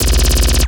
LASRGun_Alien Handgun Burst_01_SFRMS_SCIWPNS.wav